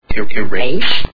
The sound bytes heard on this page have quirks and are low quality.